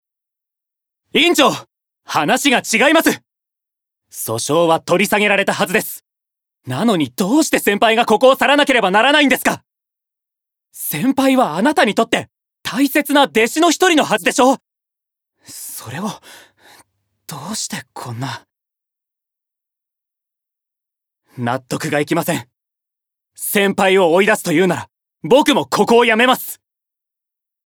Voice Sample
ボイスサンプル
セリフ２